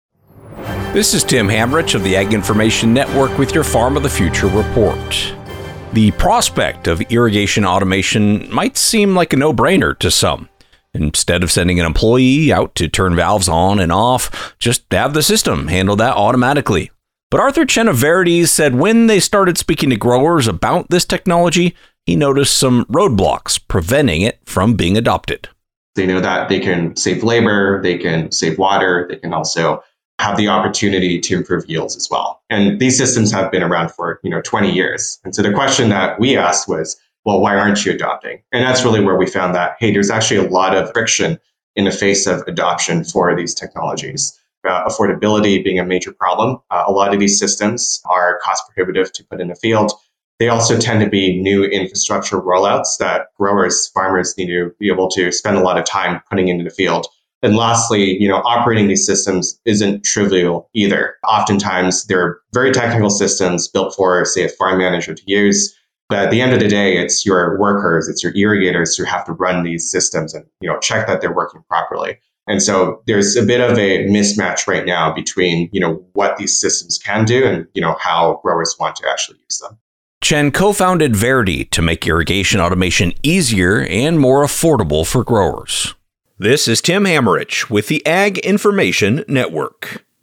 News Reporter